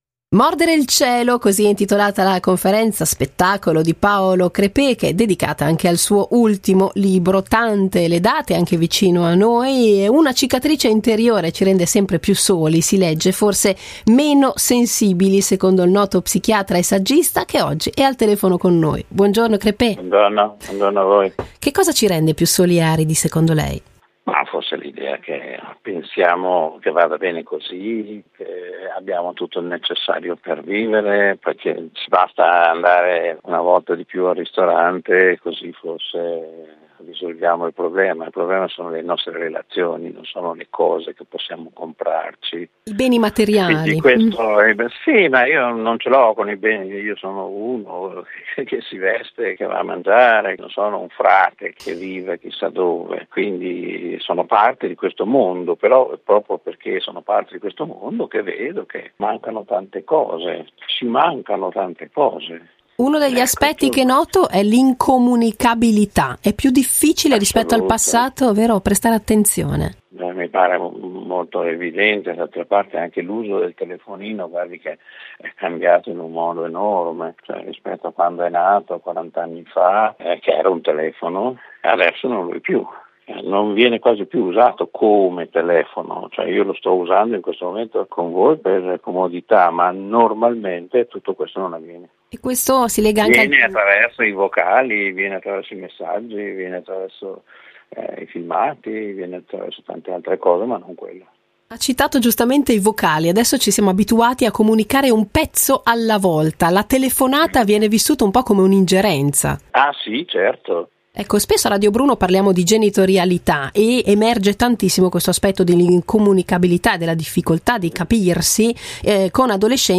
Home Magazine Interviste Paolo Crepet presenta “Mordere il cielo”